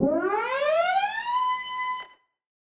Index of /musicradar/future-rave-samples/Siren-Horn Type Hits/Ramp Up
FR_SirHornE[up]-C.wav